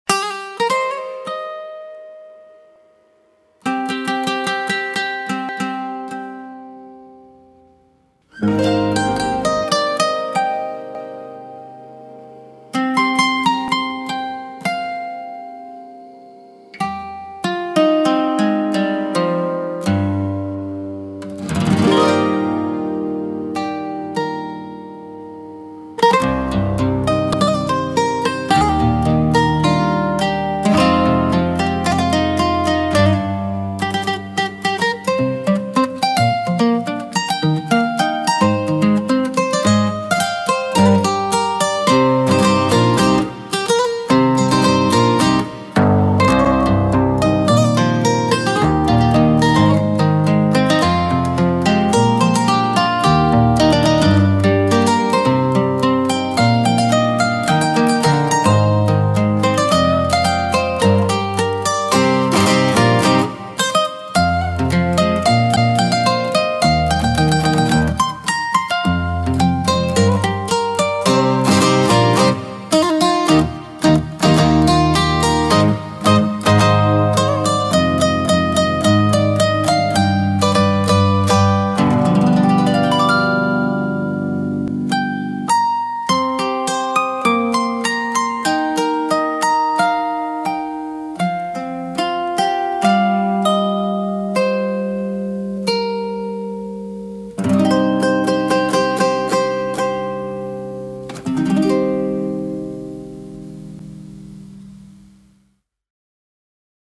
Cítara japonesa